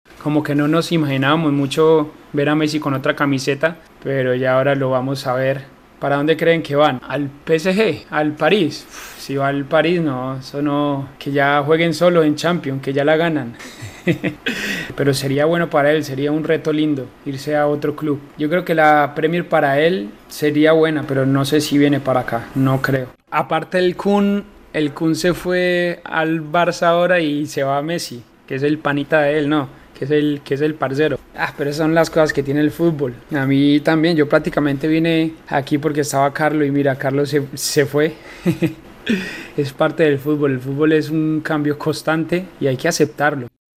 (James Rodríguez en su canal de Twitch)